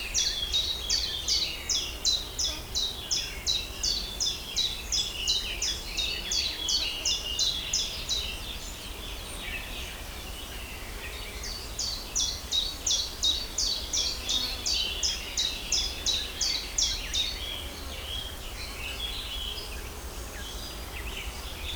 Zilpzalp Gesang
• Der Name „Zilpzalp“ ist eine Lautnachahmung seines Gesangs.
Der-Zilpzalp-Gesang-Voegel-in-Europa.wav